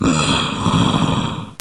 zombie_idle1.mp3